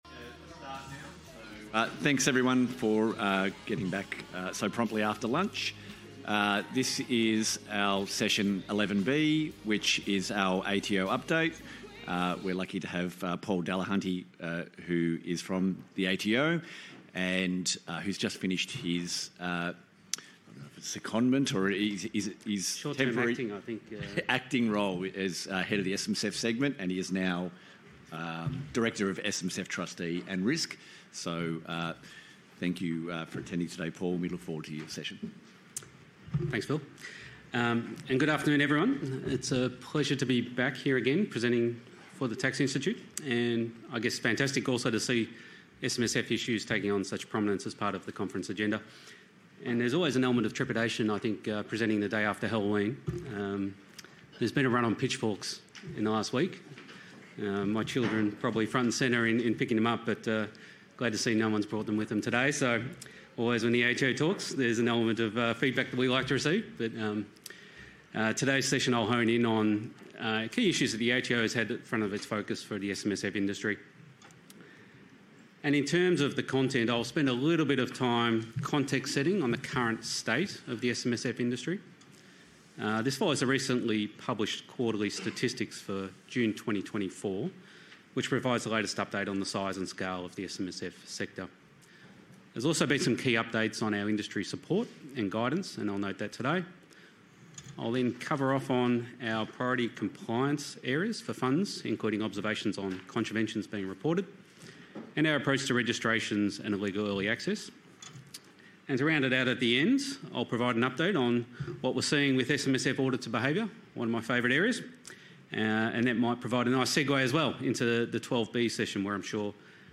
31 Oct 2024 Event Name: National Superannuation Conference Session Name
Took place at: Crown Sydney